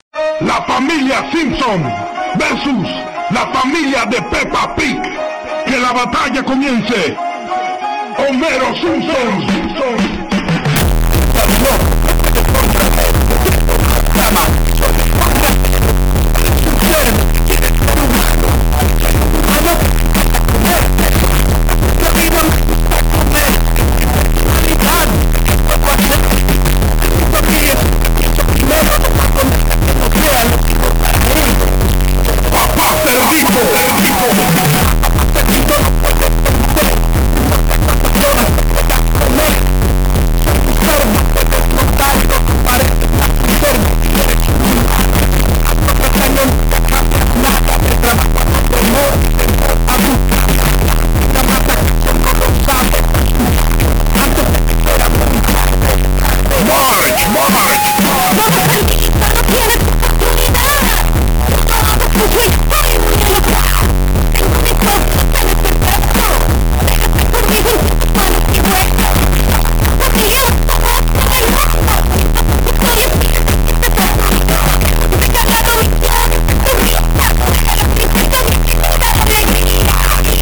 loudest earrape ever?